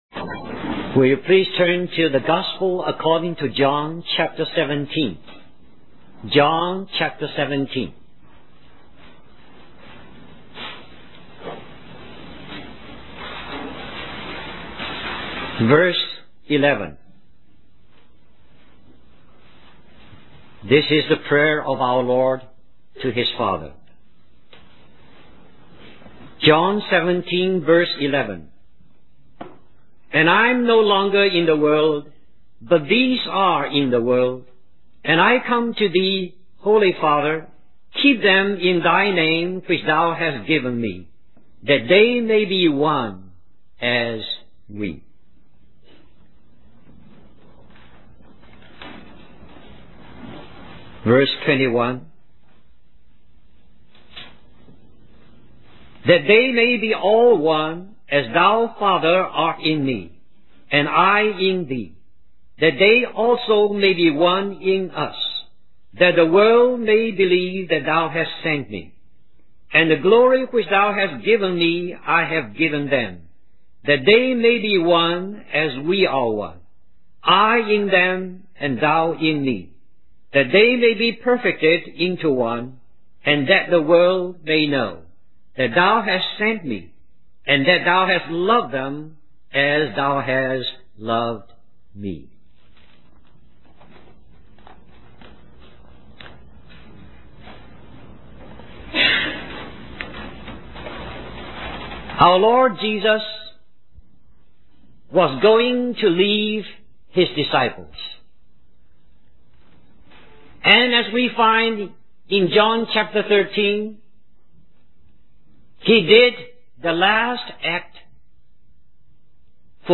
1981 Christian Family Conference Stream or download mp3 Summary In John 17, Jesus prays for His disciples and future believers, emphasizing the importance of unity among them.